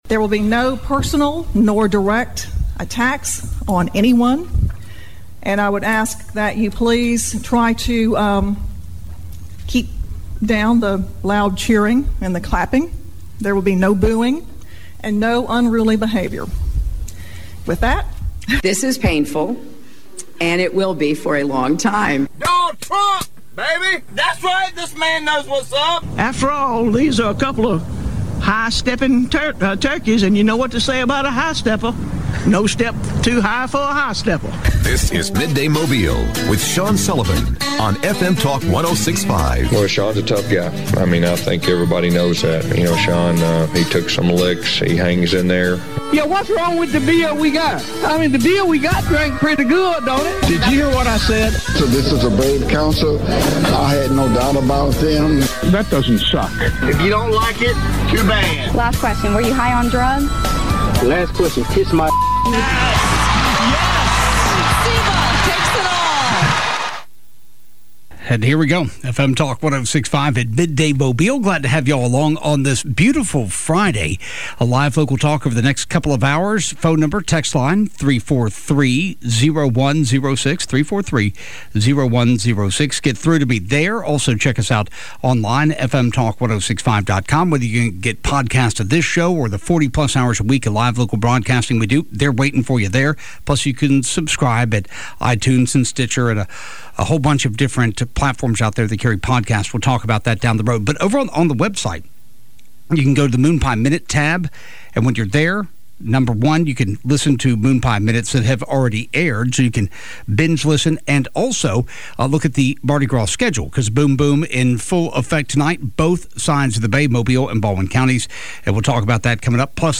talks with State Representative Chris Pringle about his proposed GIRL bill and the reaction in Montgomery.